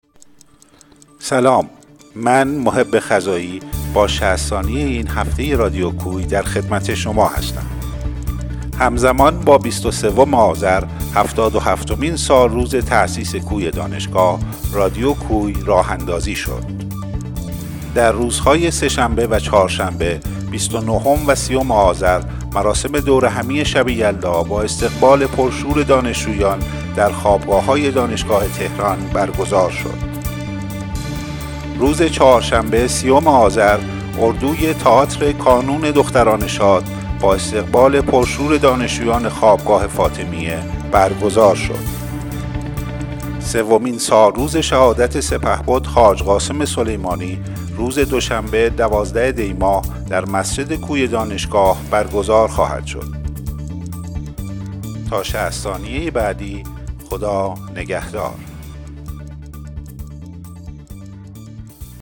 اخبار ۶۰ ثانیه‌ای [۰۷ دی ۱۴۰۱]